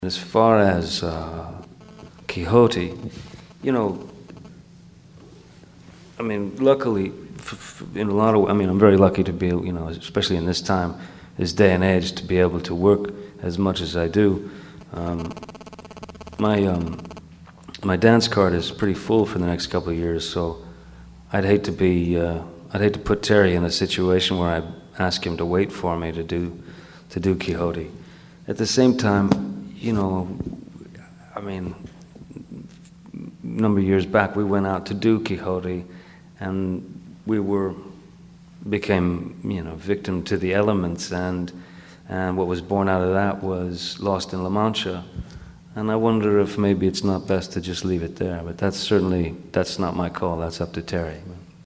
Public Enemies press junket